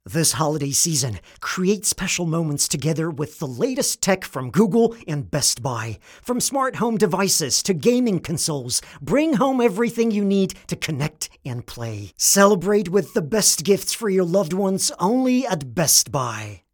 American Voice Over Talent, Artists & Actors
Adult (30-50)